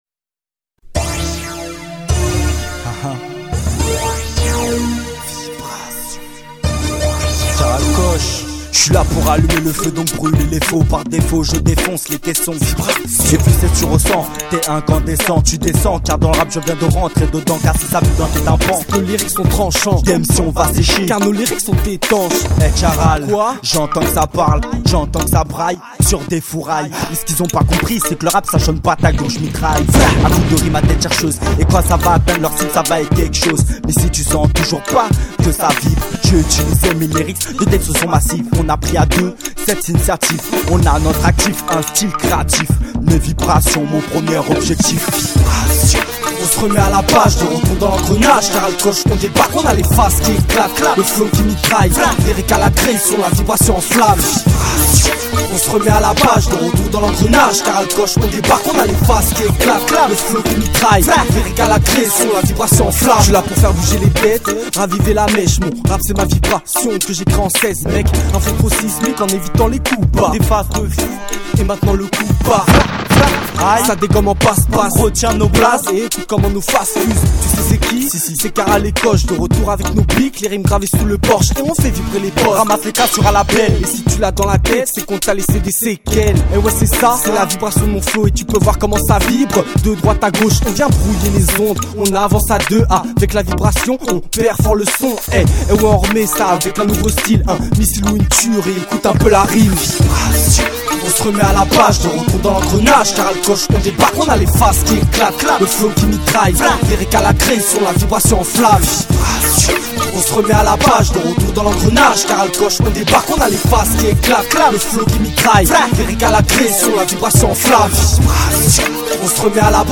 Catégorie : Rap -> Music